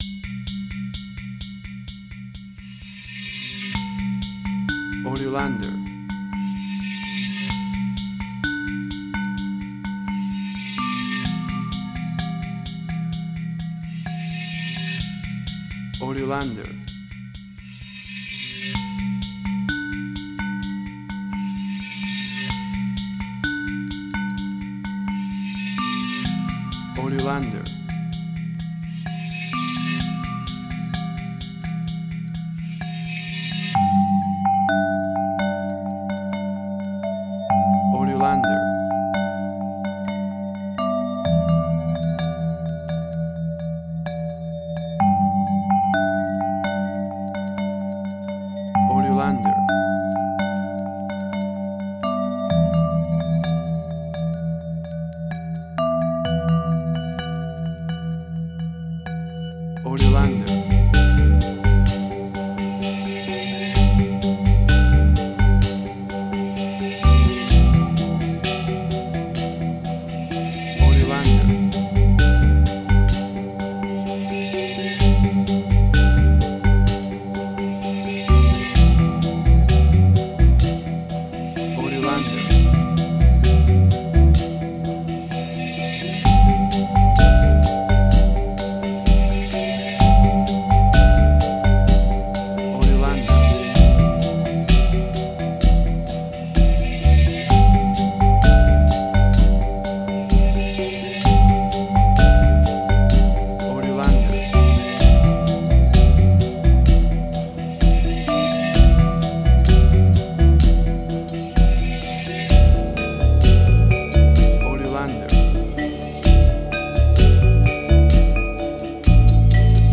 Gamelan Ethnic instrumental.
Tempo (BPM): 64